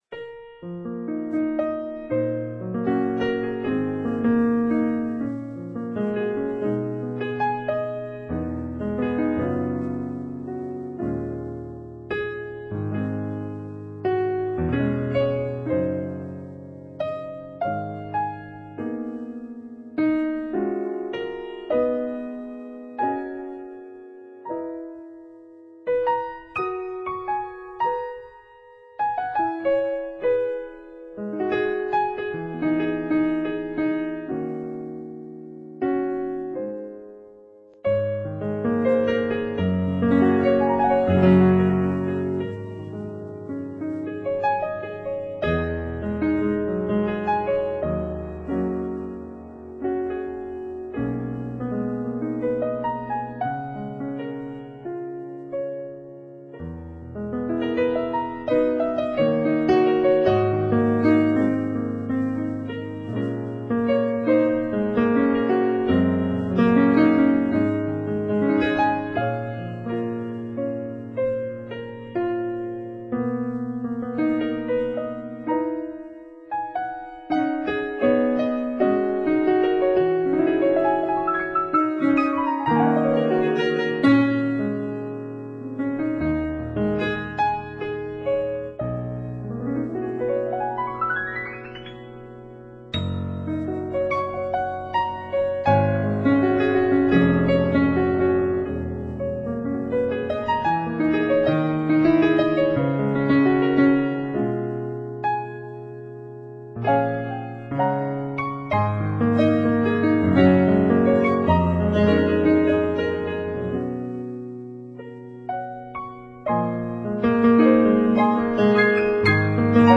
Abgelegt unter: Piano